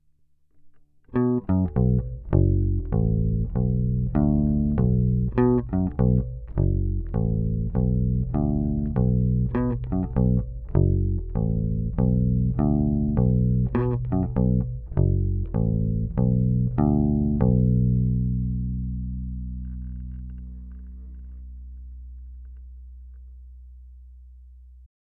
Snímač by měl být DiMarzio DP122 + přepínač serie/paralel